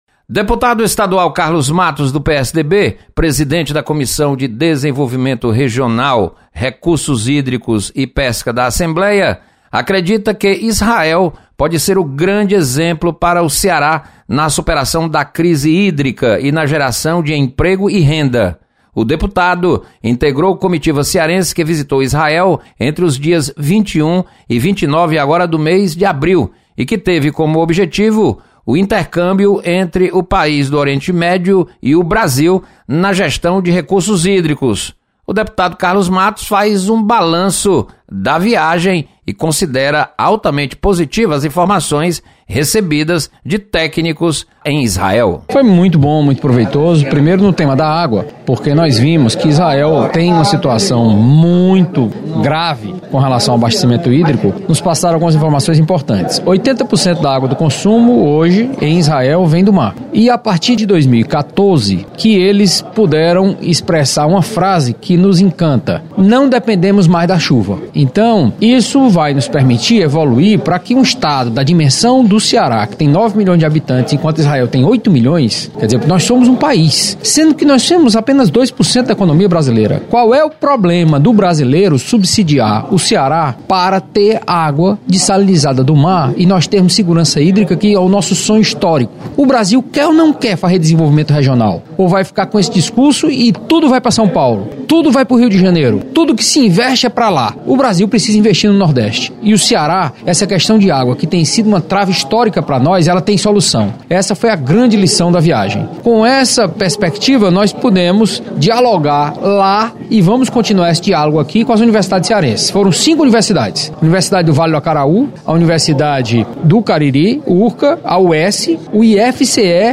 Deputado Carlos Matos relata sobre viagem à Israel, na qual buscou intercâmbio de tecnologias para recursos hídrico.